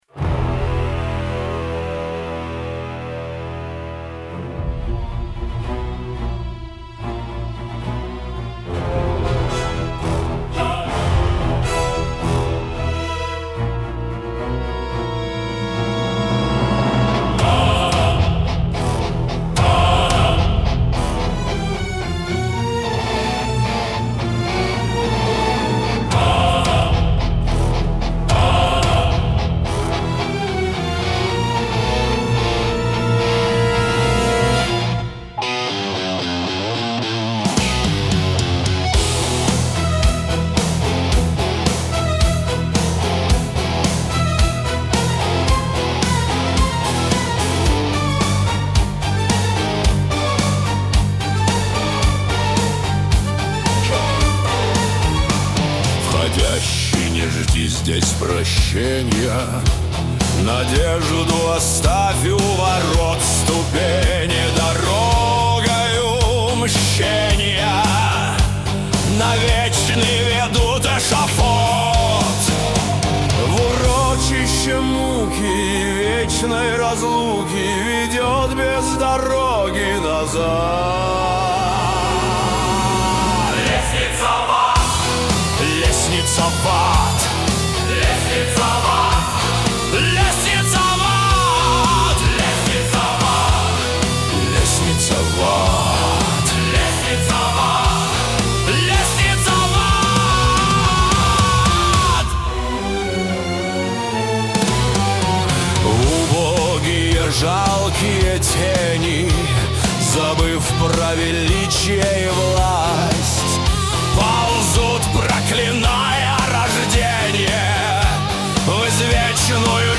Оркестровые версии(1987,2024
mp3,8137k] Металл